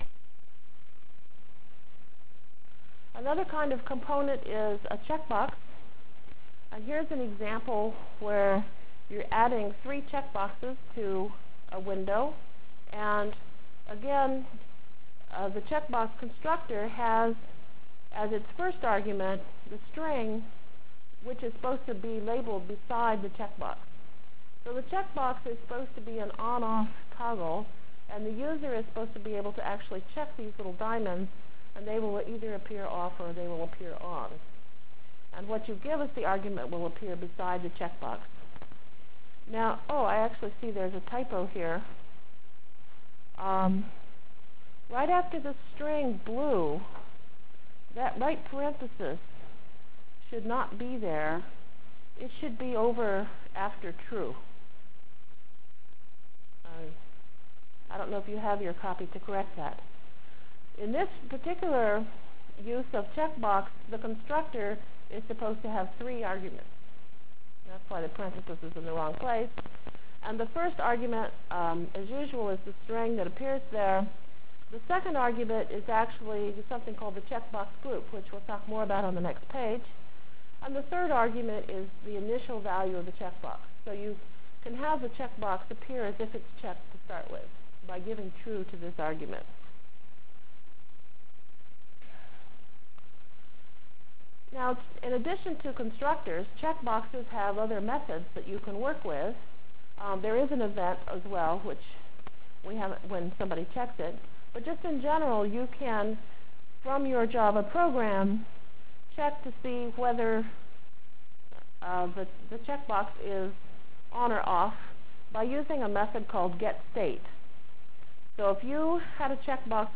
From Feb 3 Delivered Lecture for Course CPS616 -- Java Lecture 4 -- AWT Through I/O CPS616 spring 1997 -- Feb 3 1997.